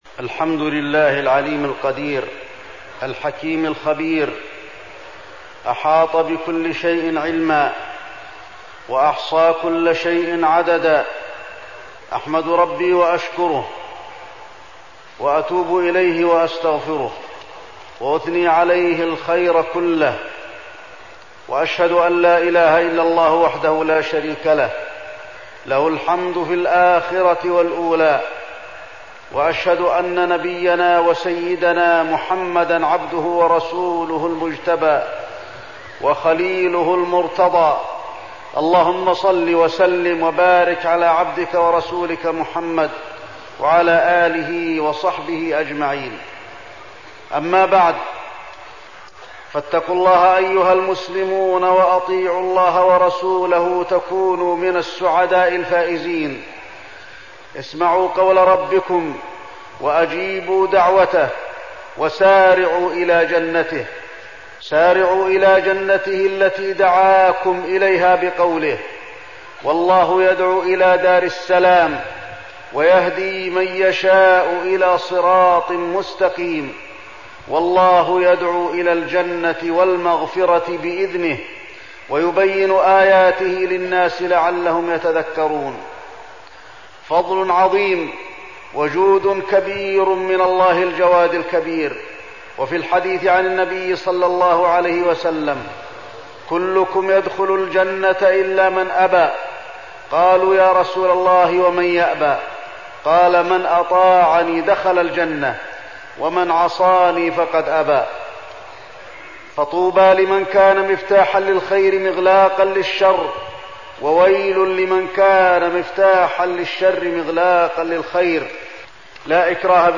تاريخ النشر ١٦ ربيع الثاني ١٤١٧ هـ المكان: المسجد النبوي الشيخ: فضيلة الشيخ د. علي بن عبدالرحمن الحذيفي فضيلة الشيخ د. علي بن عبدالرحمن الحذيفي الشيطان